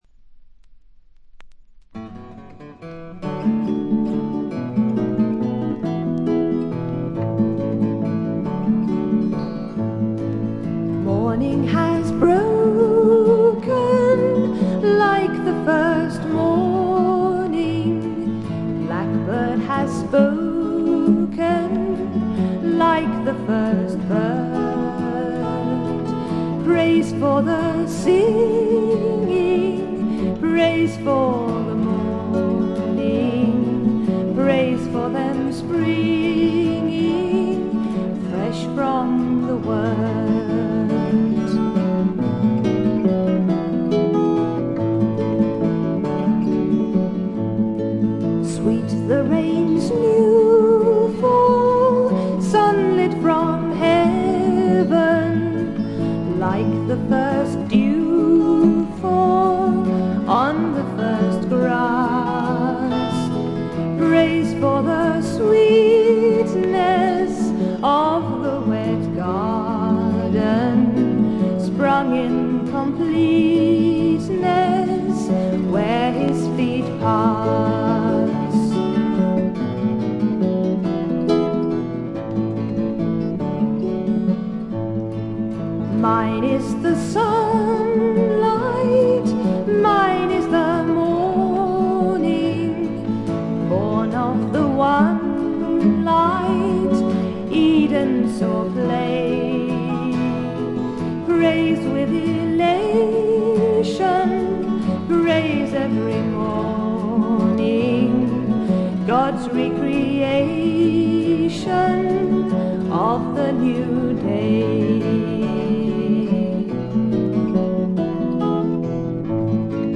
静音部でバックグラウンドノイズ、ところどころでチリプチ、散発的なプツ音少し。
試聴曲は現品からの取り込み音源です。